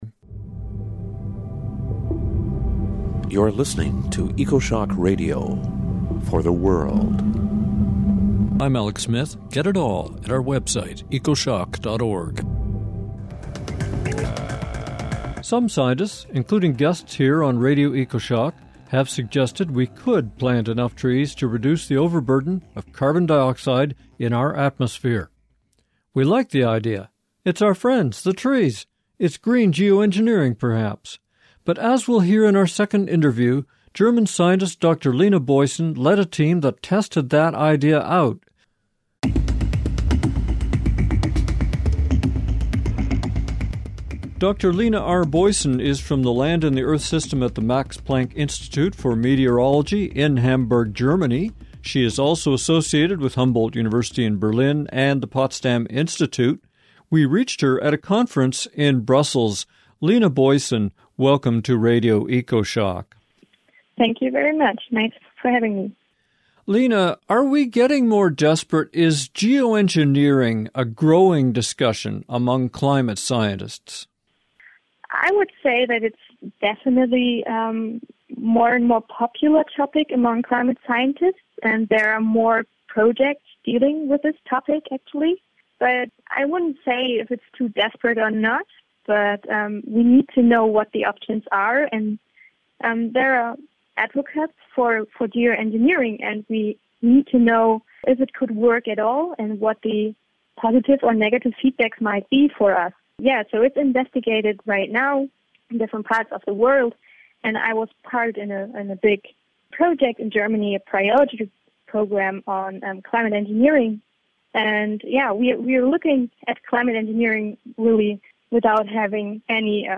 But can planting billions more trees save us from dangerous climate change? Find out in our second interview